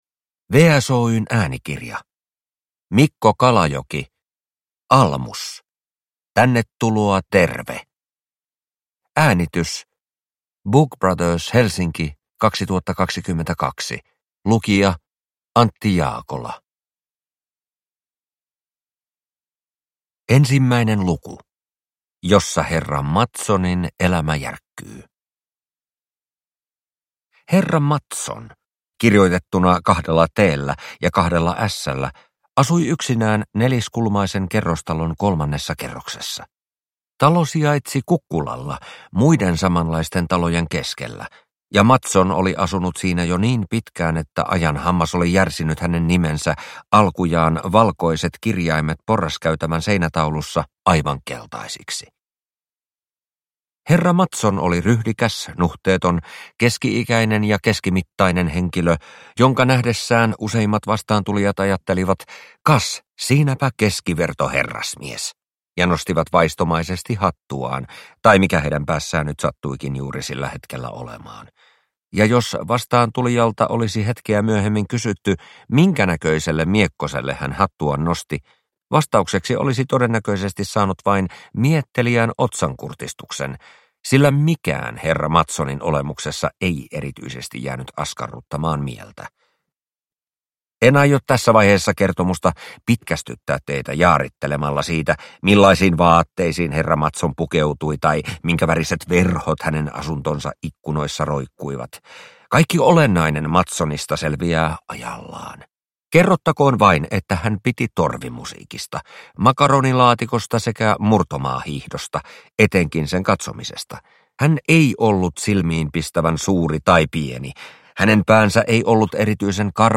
Almus: Tännetuloa terve – Ljudbok